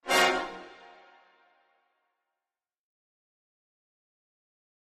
Brass Section, Short Reminder, Type 1 - Single